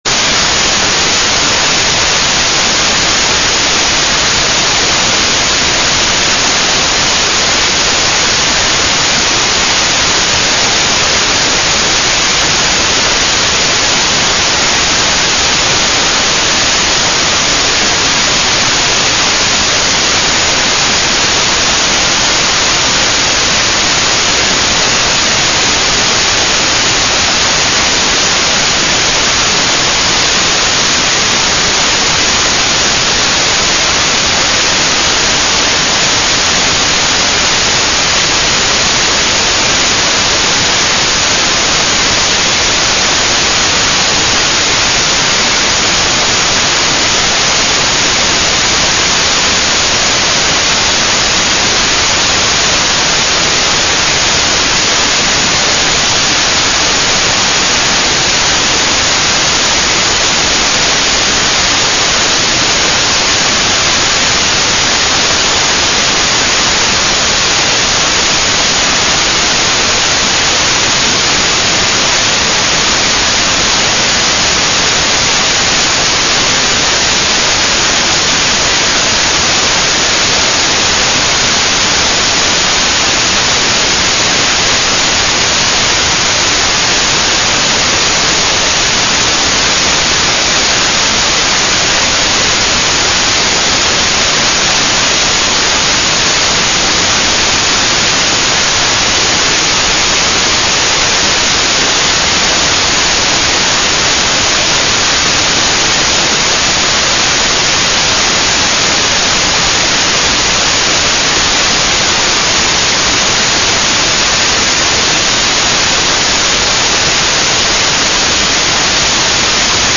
E’ il suono che si può sentire quando si sintonizza una radio in una frequenza senza emittenti, che è simile ad un fruscio.
WhiteNoise.mp3